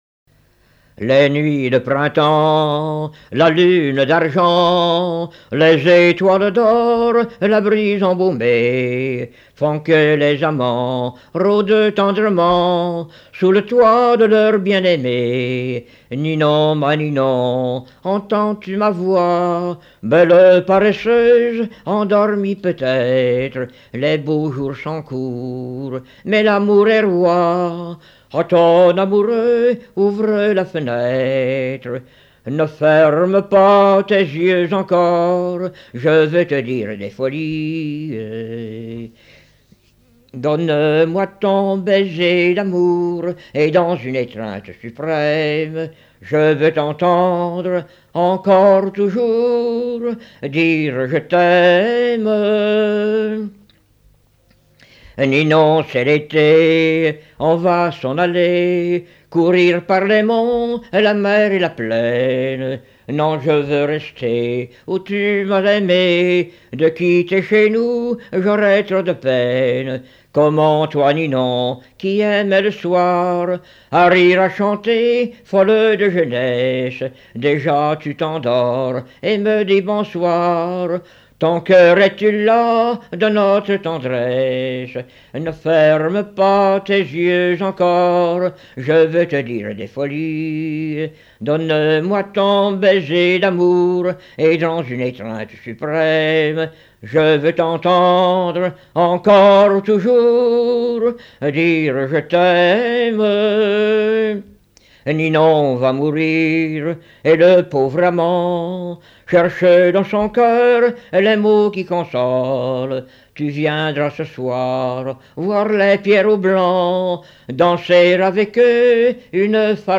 Genre strophique
Chansons du début XXe siècle
Pièce musicale inédite